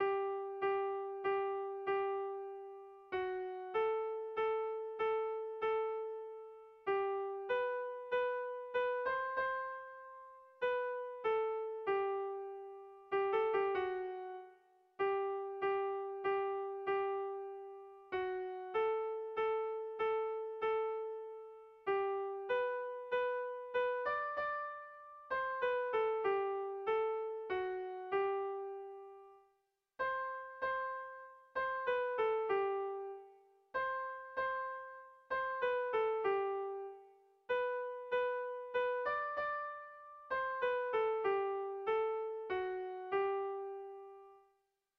Erlijiozkoa
Seiko handia (hg) / Hiru puntuko handia (ip)
A1A2B